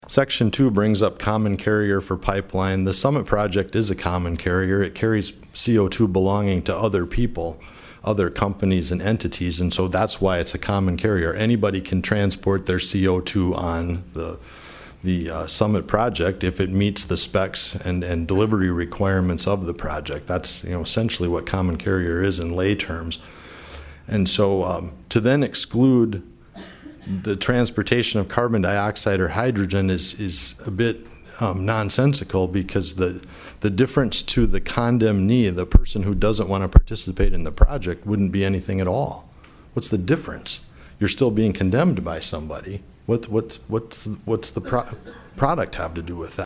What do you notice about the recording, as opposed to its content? PIERRE, S.D.(HubCityRadio)- The South Dakota Senate State Affairs Committee heard testimony on SB49.